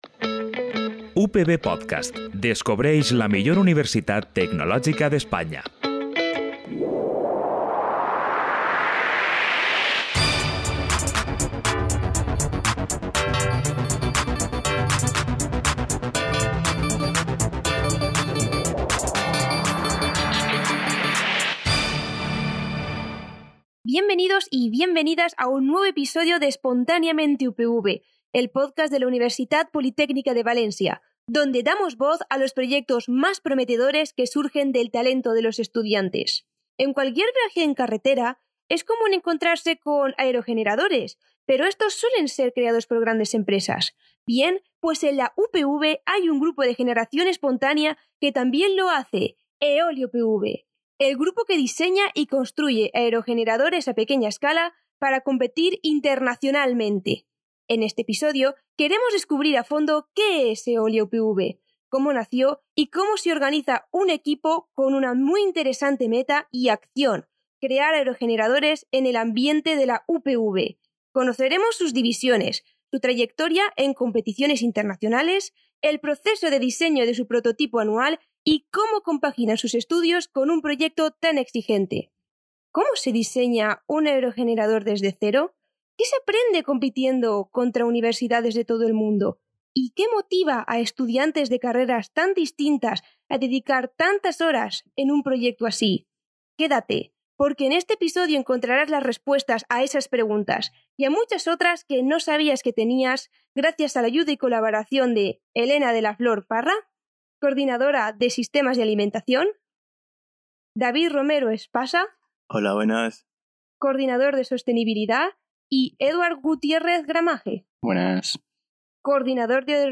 Una conversación llena de energía, creatividad y ambición tecnológica.